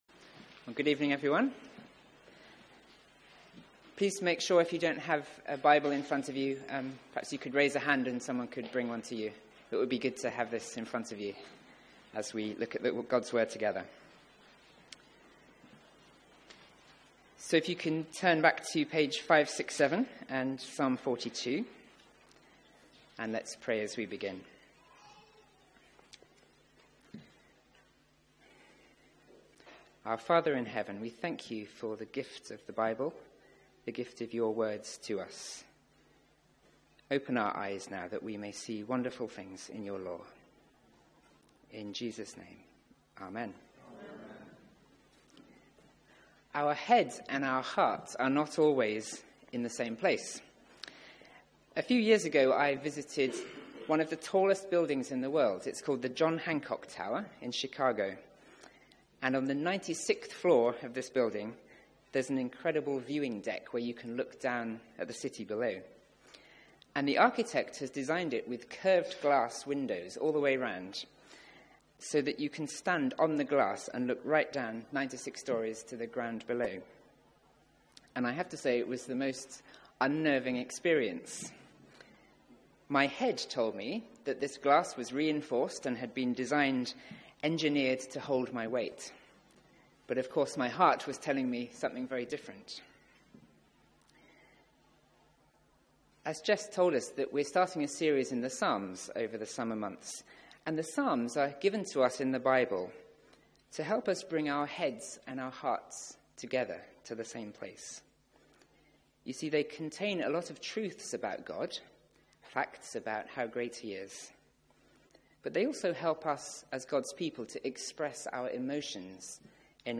Media for 6:30pm Service on Sun 23rd Jul 2017 18:30 Speaker
Series: Summer Psalms Theme: A God for the downcast Sermon